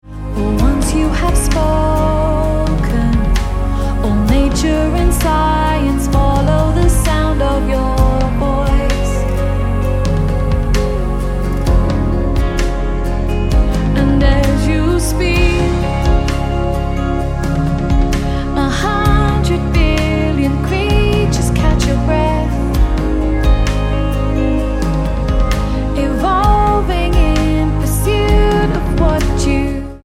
C#